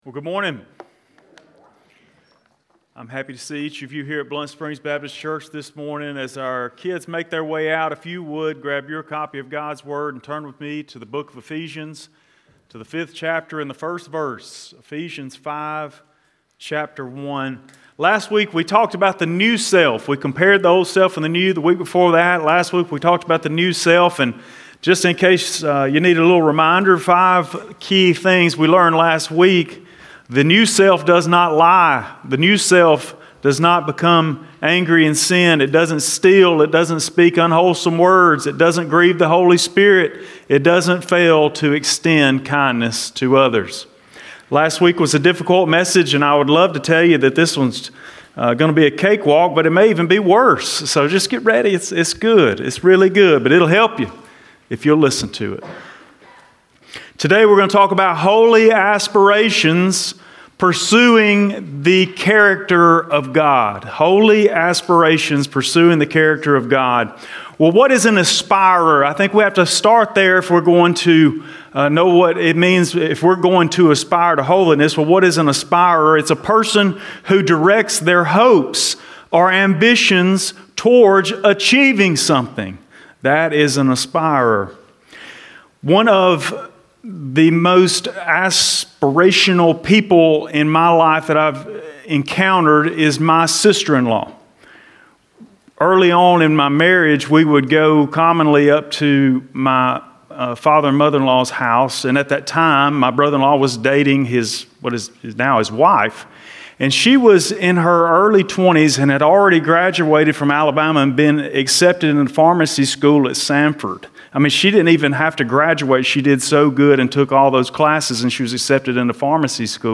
Holy Aspirations: Pursuing the Character of God | Ephesians 5:1-7 | Sunday Morning Sermon